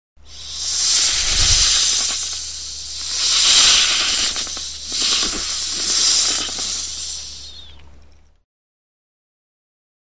Download Snake sound effect for free.
Snake